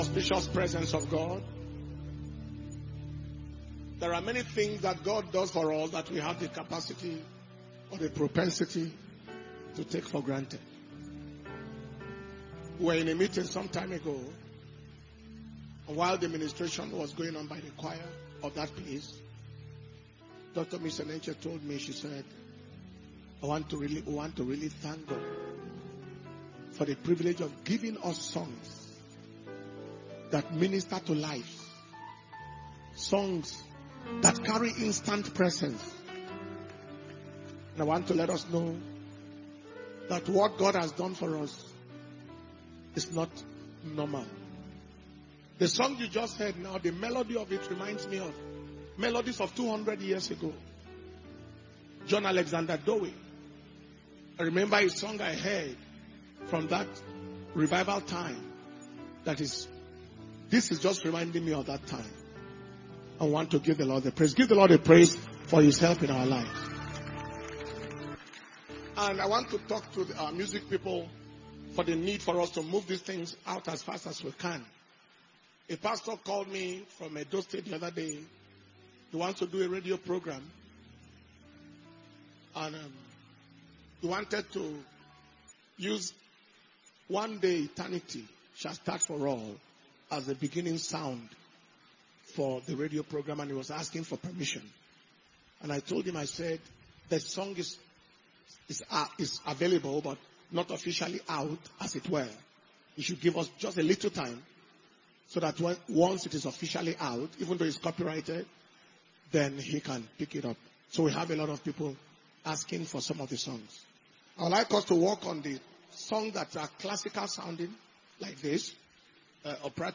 Power Communion Service - April 24th, 2024.